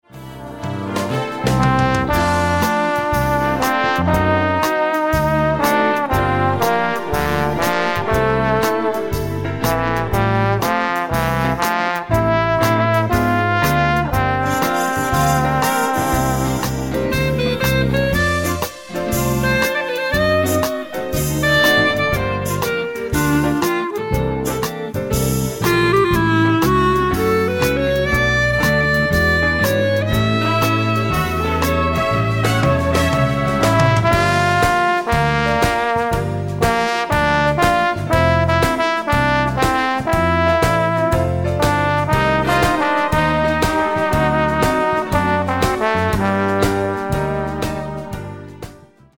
SWING  (3.39)